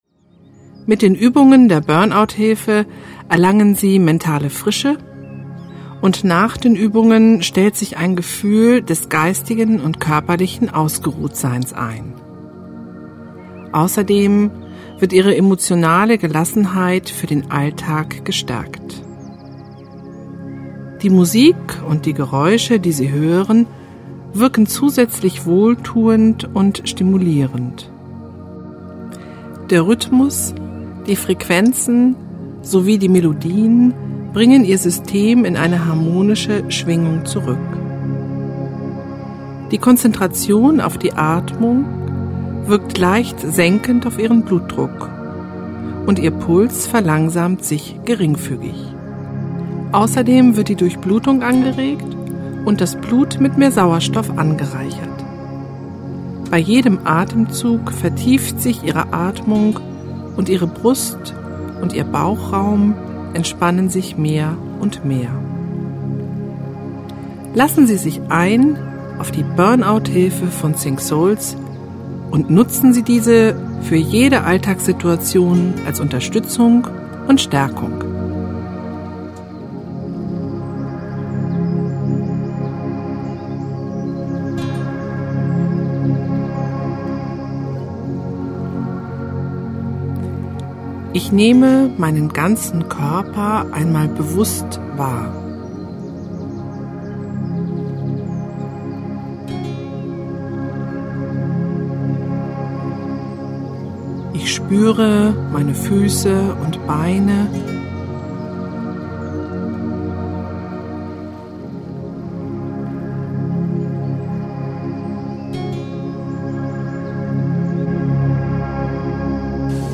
Weibliche Stimme   15:46 min